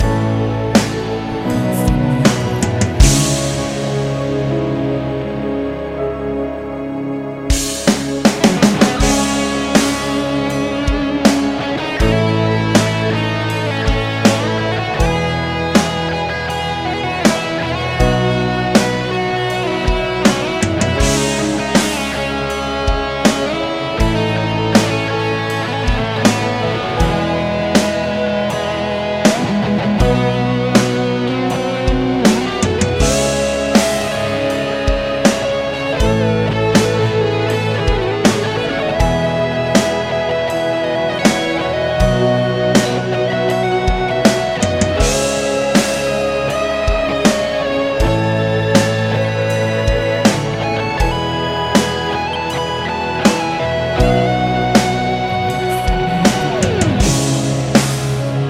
solo guitarra
esta é a primeira versão do solo da guitarra, ainda estamos compondo a versão opriginal